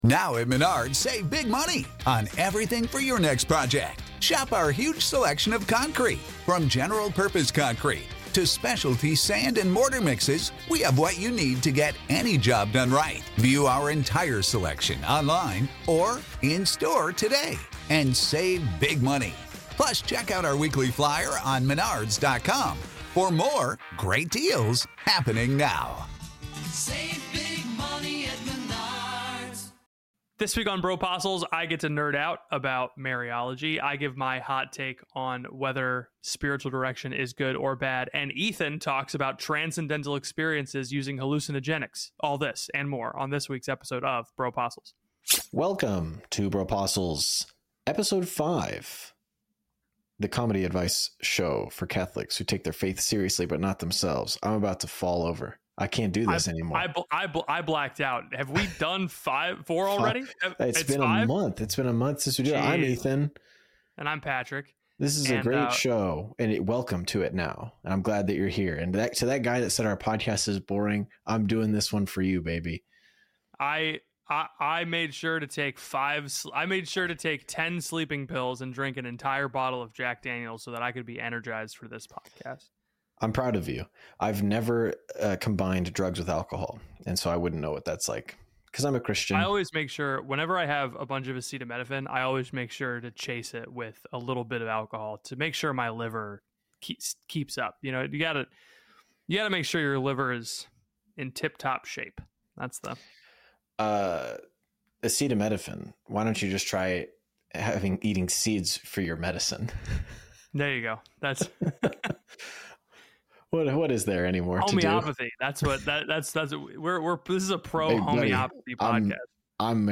Welcome to Bropostles the only podcast with two guys who were perhaps the biggest thing that happened in Catholicism this week.